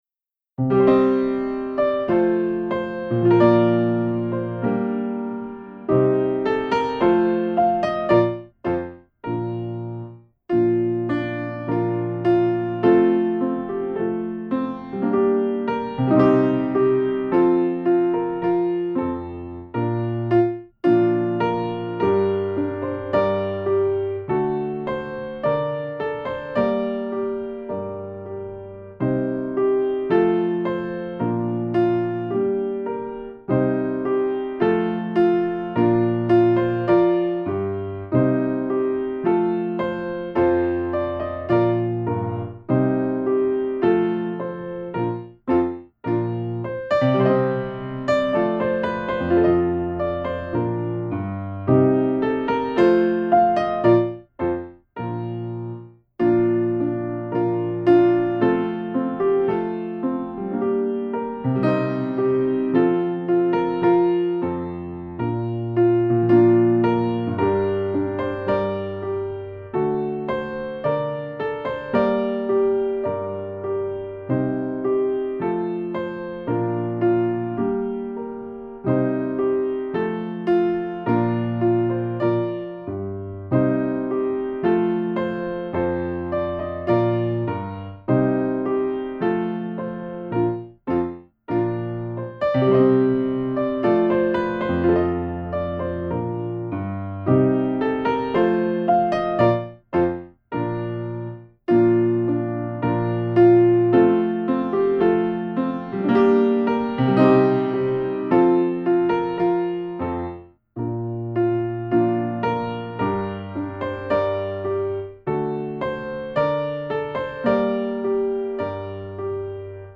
Nástroj: spev
Folk song